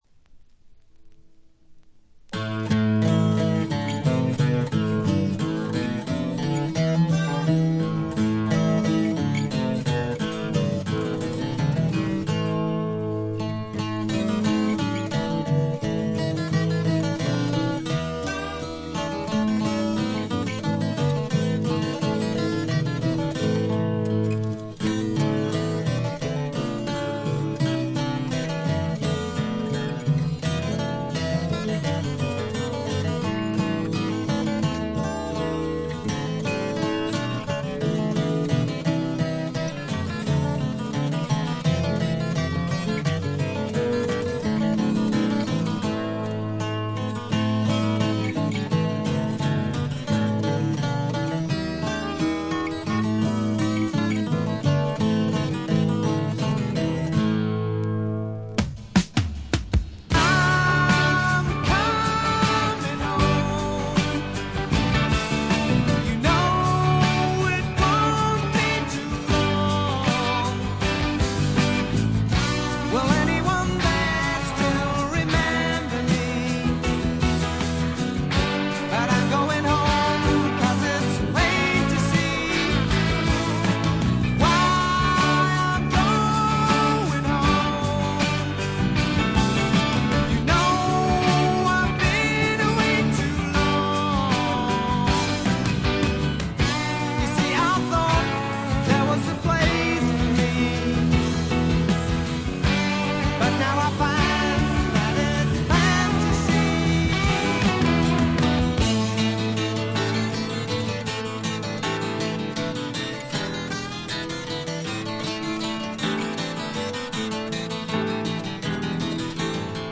部分試聴ですがチリプチ少々。
当時の流行であったスワンプと英国流ポップを合体させたような素晴らしい作品に仕上がっています。
試聴曲は現品からの取り込み音源です。
Recorded at Dick James Studio, London.